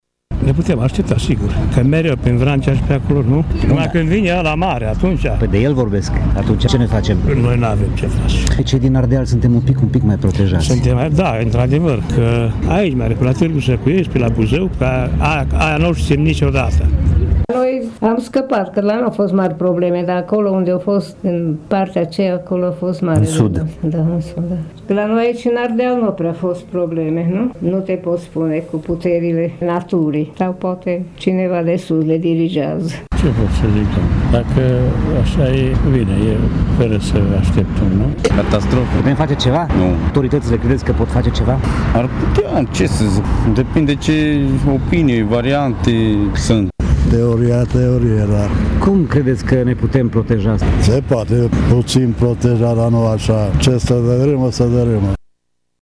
Oamenii cu care am stat de vorbă nu s-au arătat foarte panicați de eventualitatea unui mare cutremur, ei spunând că așa ceva nu poate fi nici prevăzut și nici controlat: